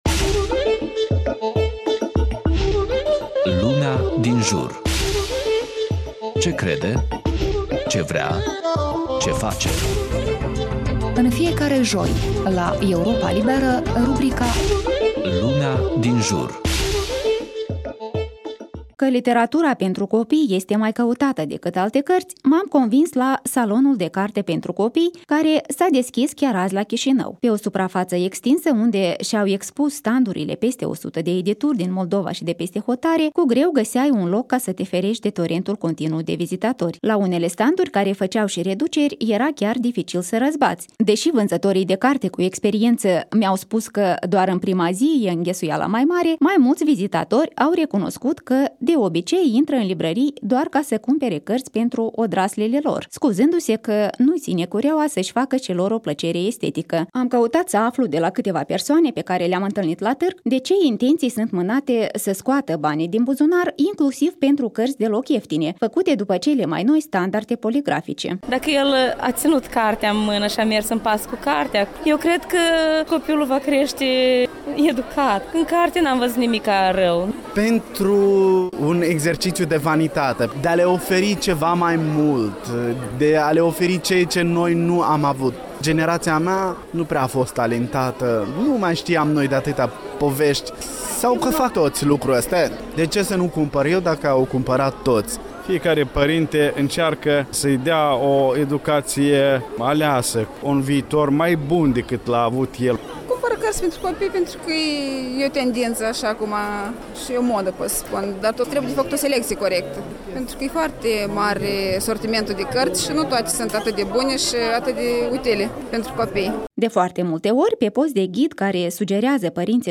Deschiderea salonului de carte pentru copii la Chișinău - părinții sfîșiați între pungă și pedagogie.
Am căutat să aflu de la cîteva persoane pe care le-am întîlnit la tîrg de ce intenţii sînt mînate să scoată banii din buzunar inclusiv pentru cărţi deloc ieftine, făcute după cele mai noi standarde poligrafice.